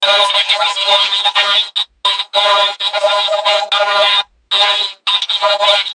robot-says-dont-do-now-lnws2cnf.wav